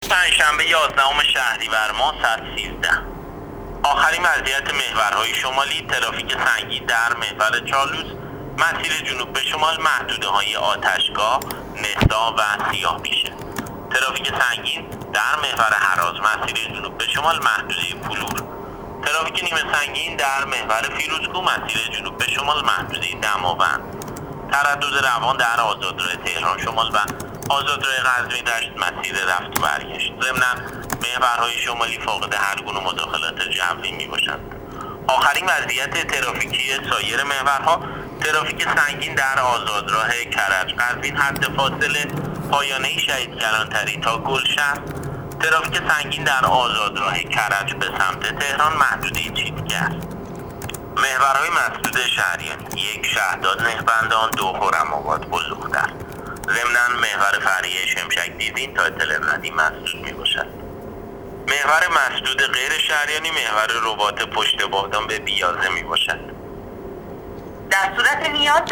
گزارش رادیو اینترنتی از آخرین وضعیت ترافیکی جاده‌ها تا ساعت ۱۳ یازدهم شهریور؛